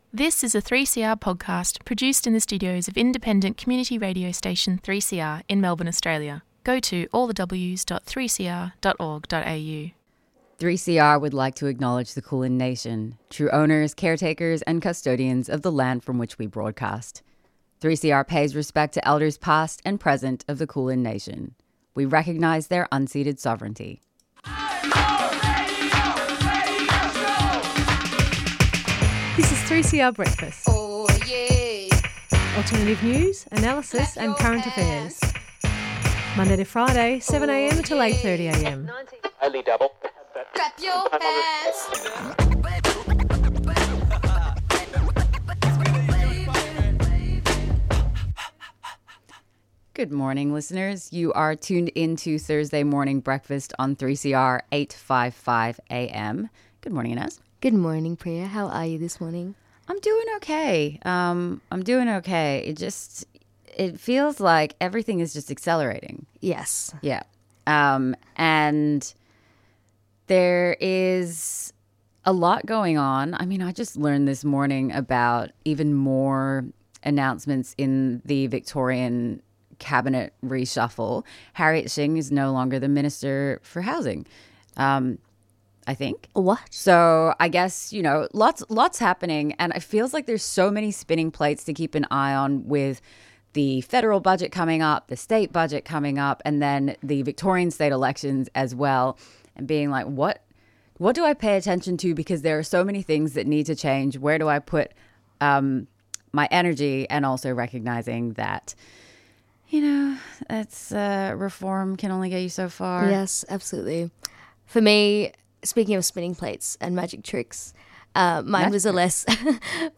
Acknowledgement of Country//